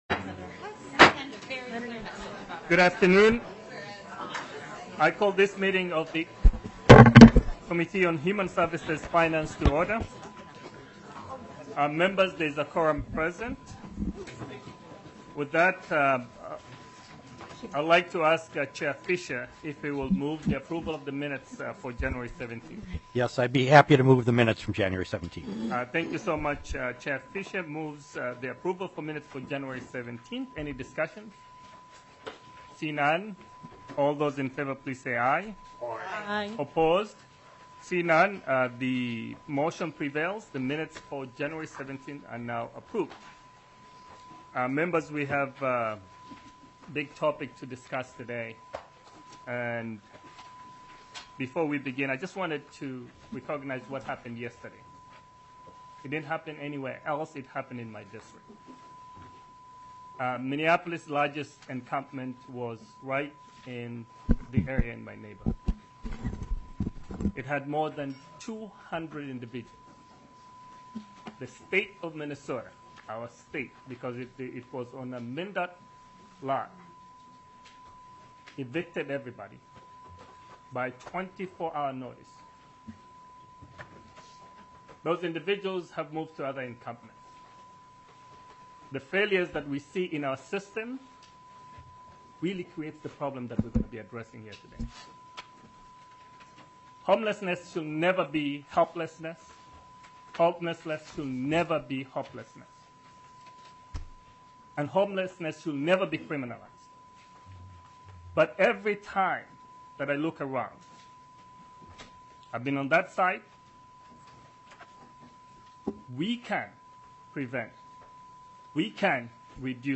Human Services Finance FOURTH MEETING - Minnesota House of Representatives
00:44 - Introductory comments by the Chair.